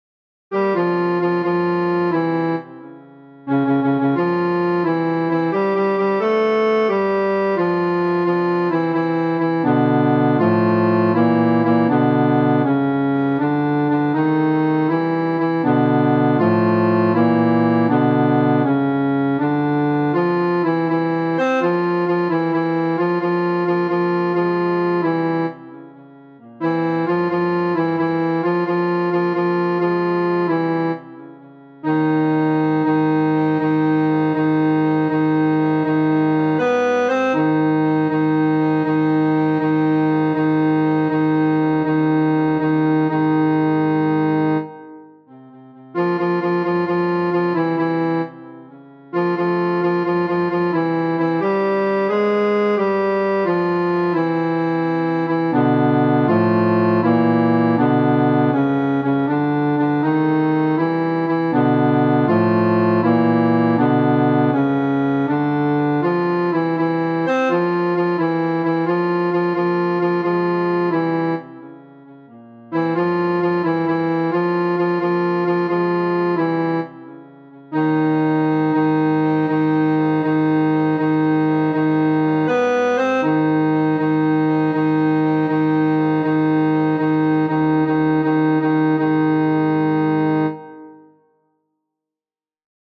FF:HV_15b Collegium musicum - mužský sbor
Krasneocitve-Bar.mp3